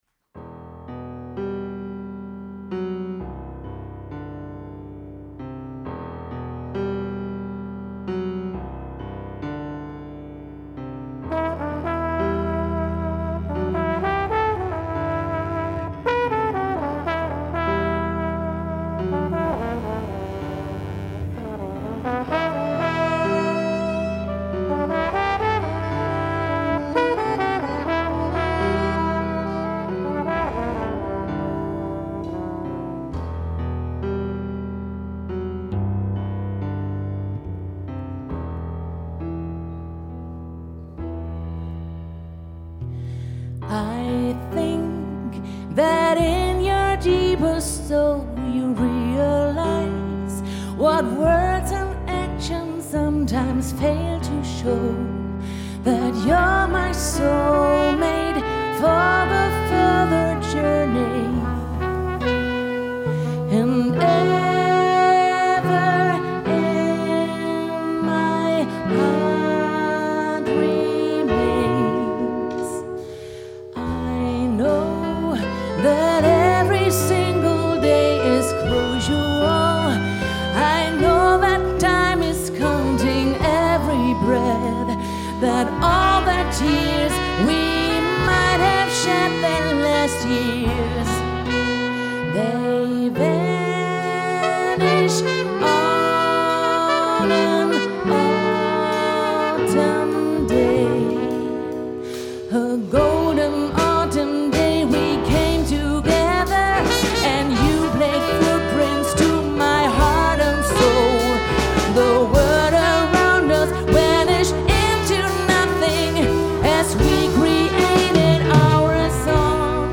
· Genre (Stil): Jazz
· Kanal-Modus: joint stereo · Kommentar